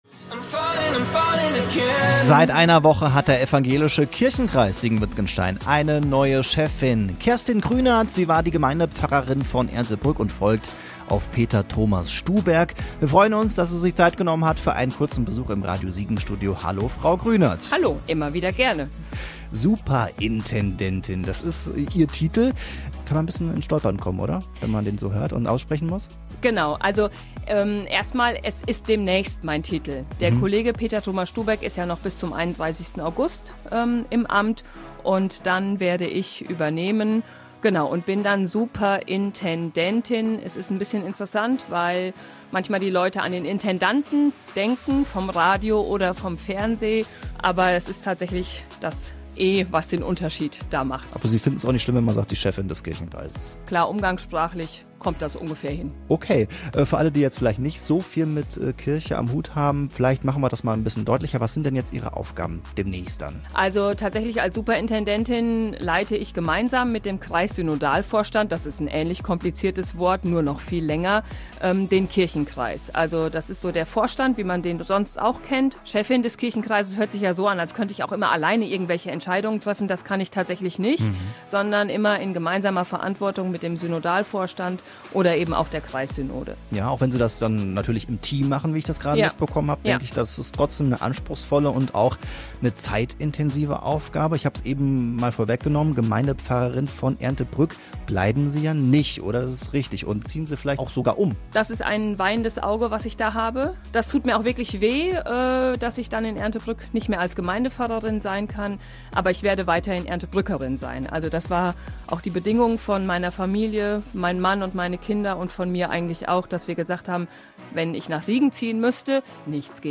Hier könnt ihr das komplette Interview nachhören: